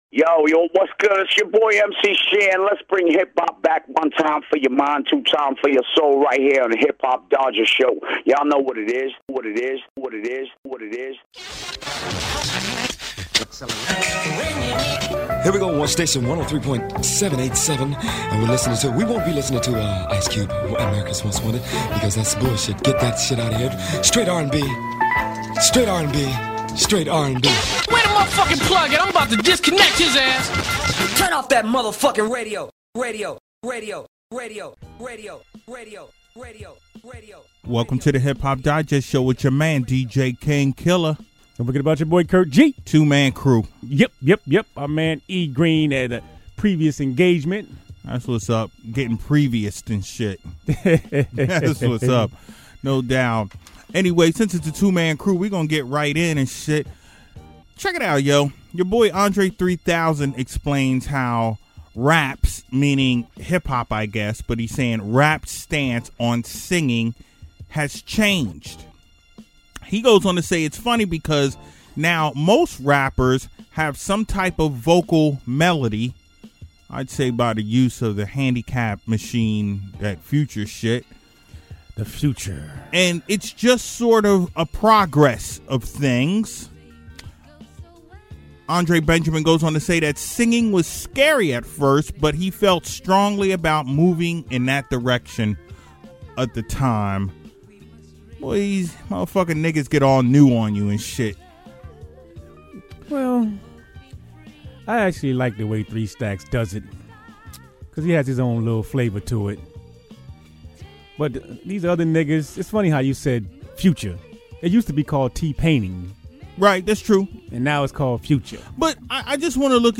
Two man show this week